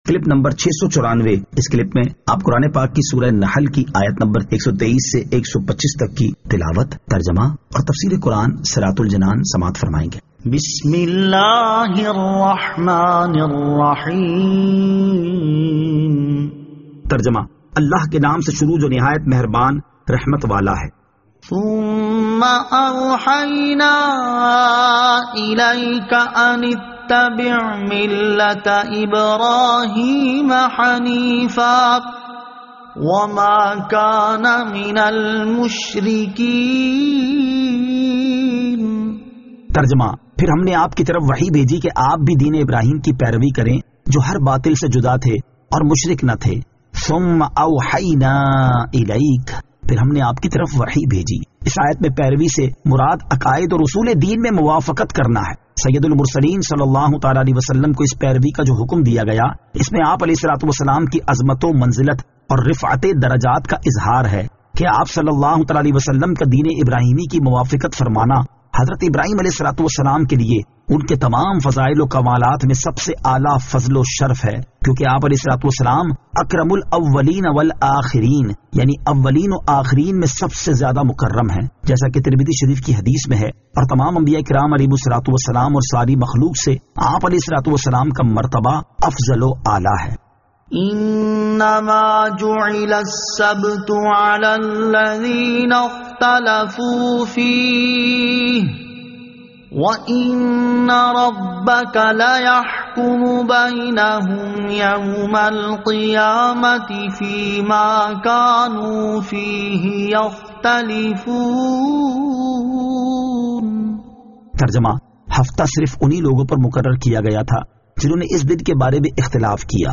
Surah An-Nahl Ayat 123 To 125 Tilawat , Tarjama , Tafseer